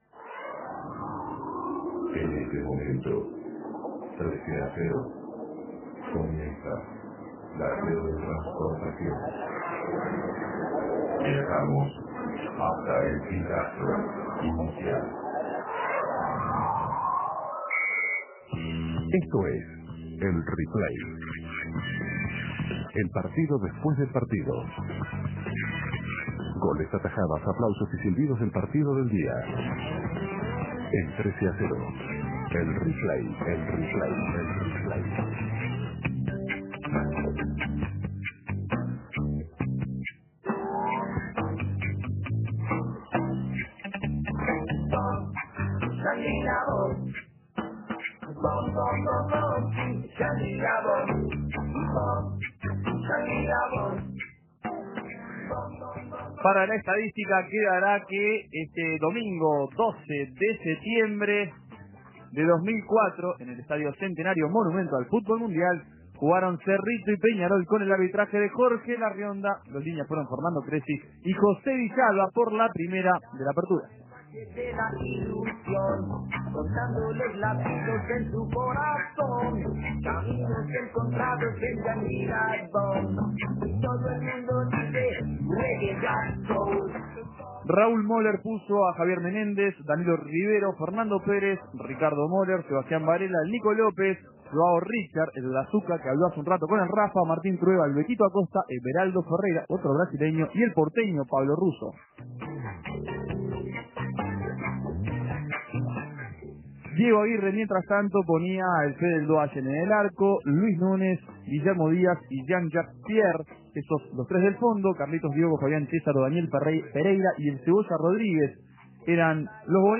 Goles y comentarios Replay: Cerrito 1 - Peñarol 1 Imprimir A- A A+ El manya dejó dos puntos en la aprtura del Apertura.